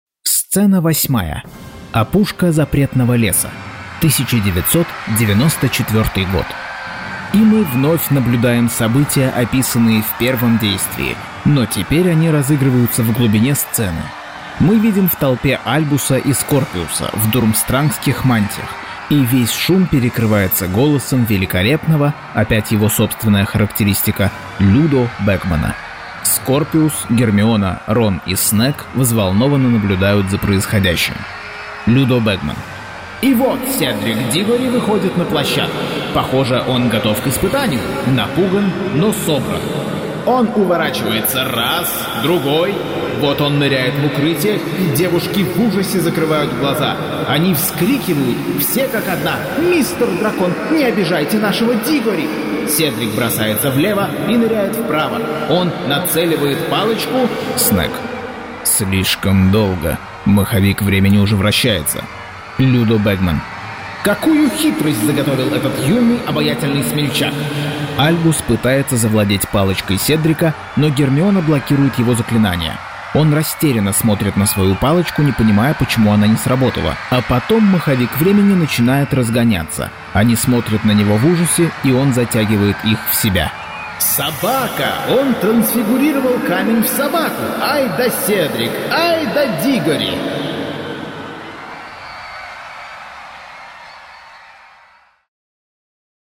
Аудиокнига Гарри Поттер и проклятое дитя. Часть 40.